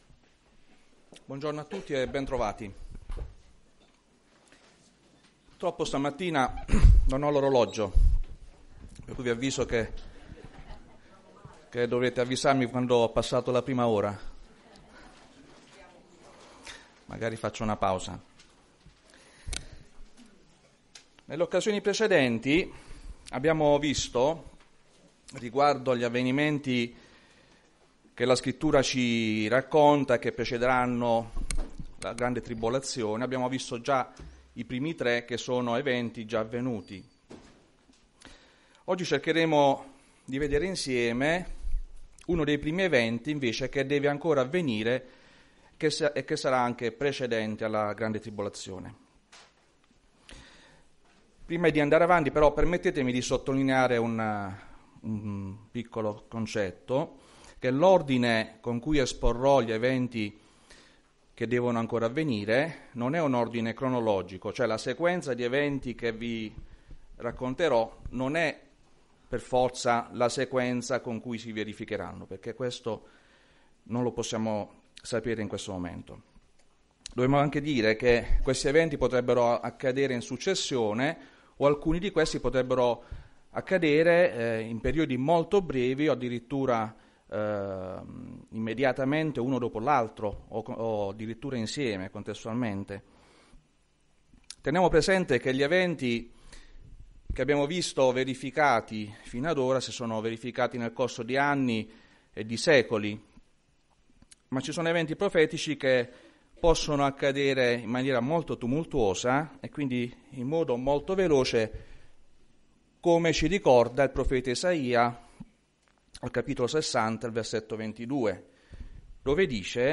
Chiesa Cristiana Evangelica - Via Di Vittorio, 14 Modena
Predicazioni